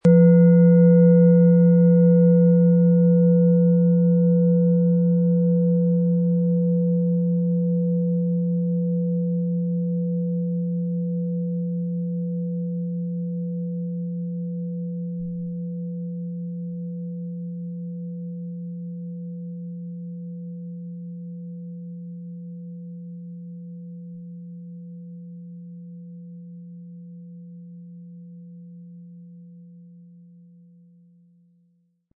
Platonisches Jahr
Planetenschale®
• Mittlerer Ton: Lilith
Spielen Sie die Schale mit dem kostenfrei beigelegten Klöppel sanft an und sie wird wohltuend erklingen.
MaterialBronze